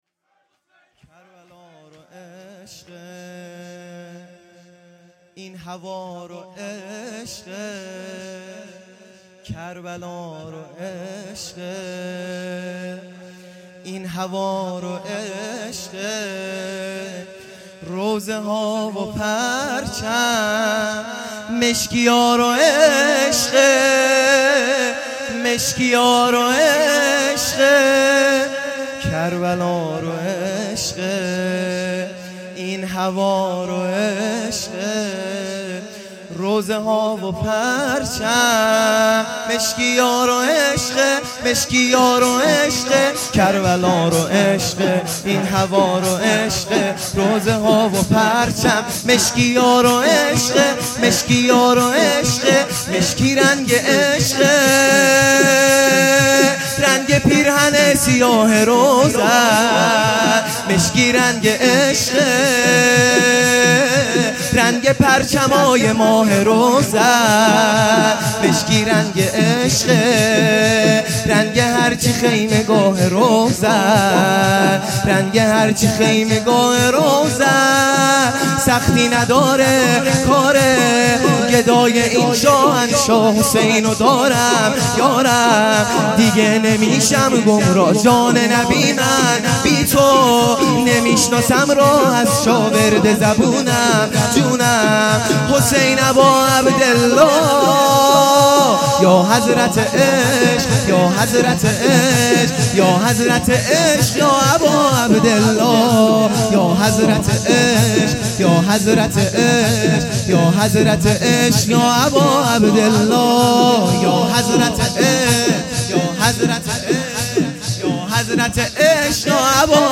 0 0 شور | مشکی رنگ عشقه
شب دوم محرم الحرام ۱۳۹۶